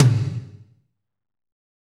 Index of /90_sSampleCDs/Northstar - Drumscapes Roland/DRM_Fast Rock/TOM_F_R Toms x
TOM F RHM0BR.wav